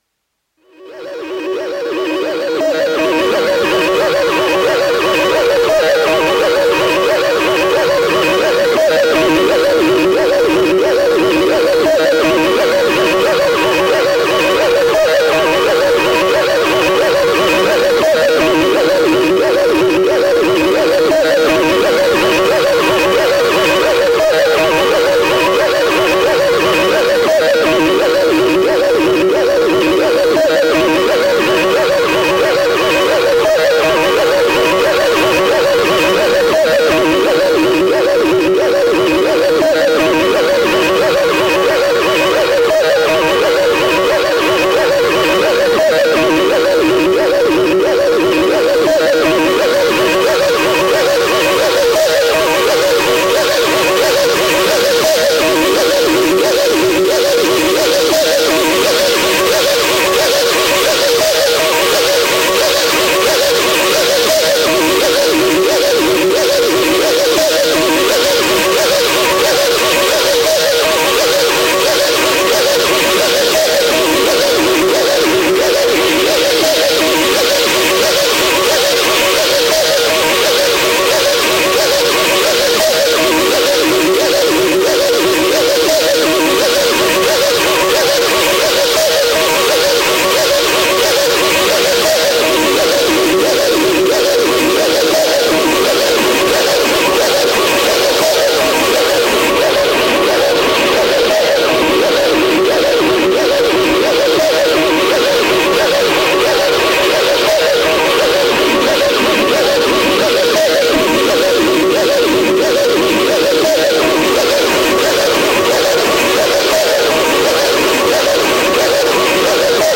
残念ながら重力効果は使っていません。素材はSynthProbeのみで後はLiveにぶち込みました。 HAARRRRRRRRP (mp3) …すみません、また基地外みたいな音になってしまいますた。